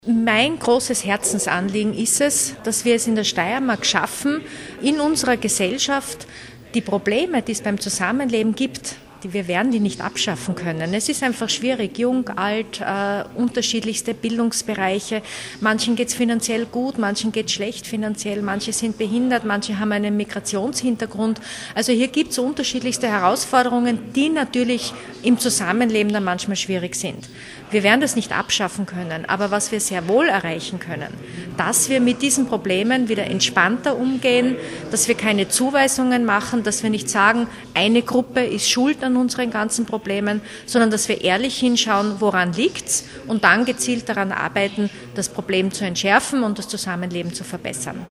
O-Ton: Partnerschaften mit dem Integrationsressort
Integrationslandesrätin Bettina Vollath: